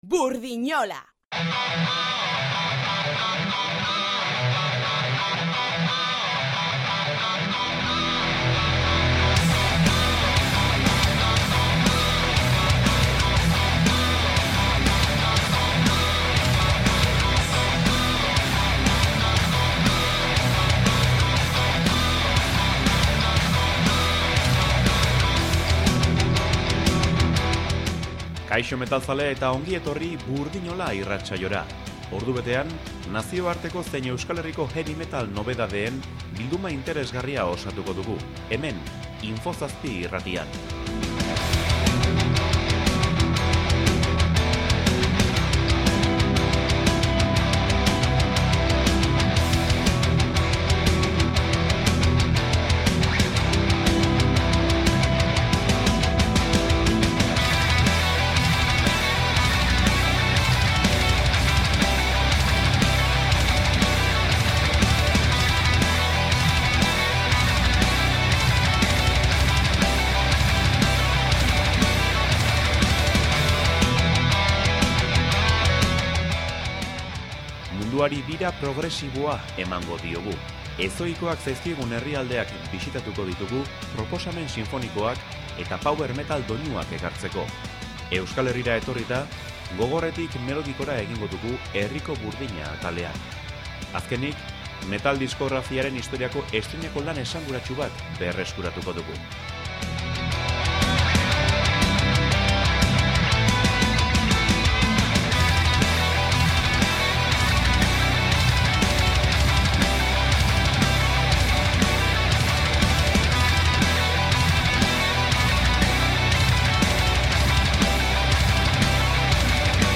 Euskarazko thrash metal belaunaldi berria izan dugu Burdinola heavy metal irratsaioan